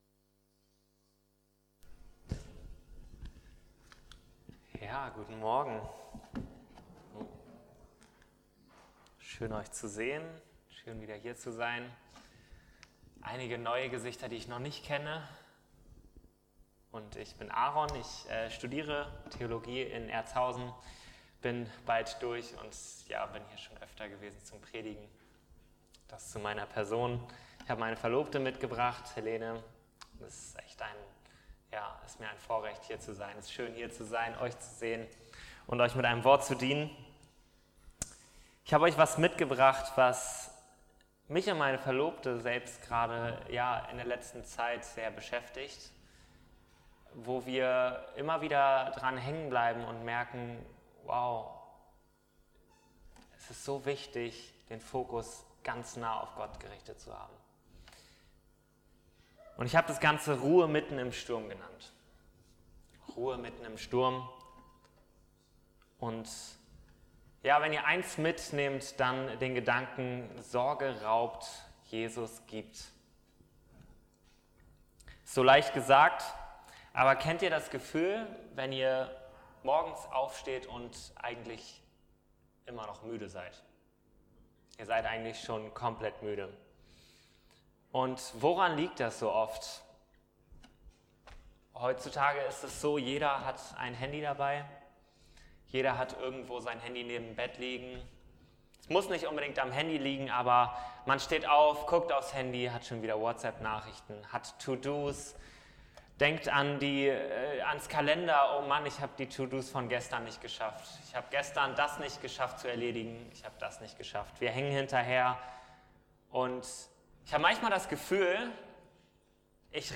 Matthäus 6,25-29 Dienstart: Predigt Wir verlieren uns im Alltag oft in Ängste und Sorgen und versuchen unsere Zukunft bereits unter Kontrolle zu haben.